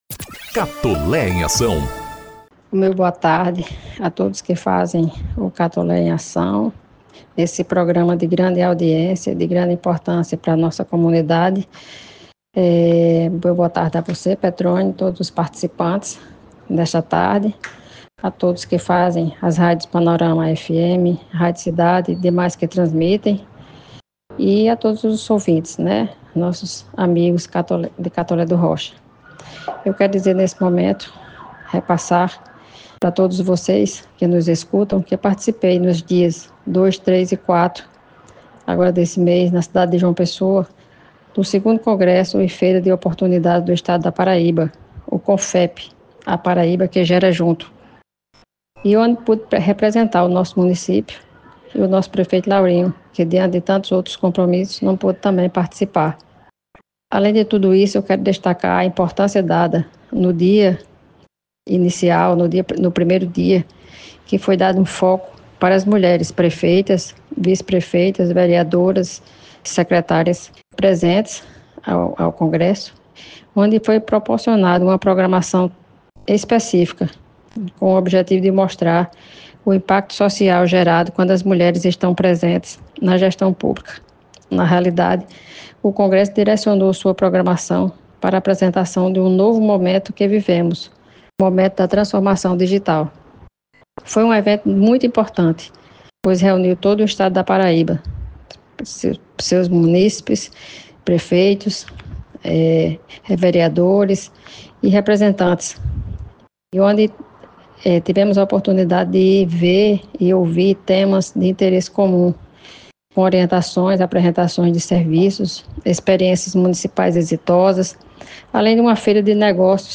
🎧 Clique aqui para ouvir o áudio da vice-prefeita Drª Paulina Maia falando sobre a importância do congresso para Catolé do Rocha.